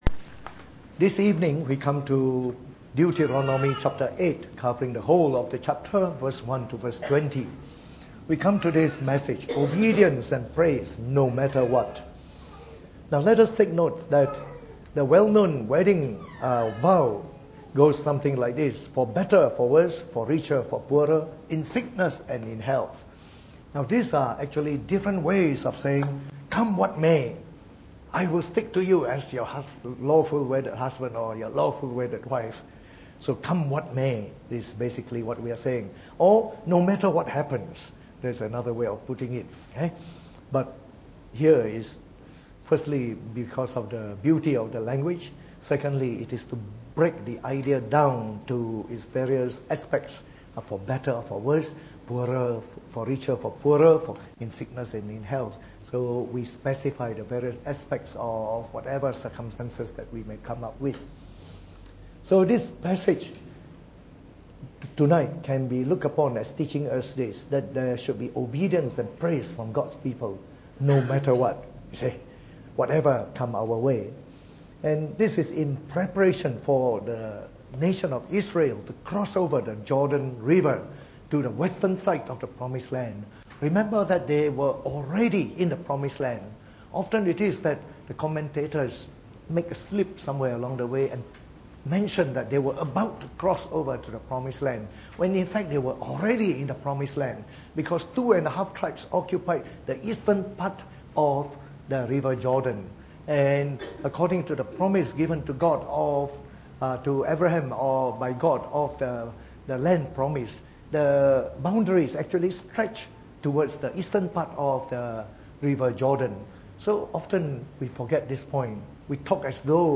Preached on the 7th of March 2018 during the Bible Study, from our series on the book of Deuteronomy.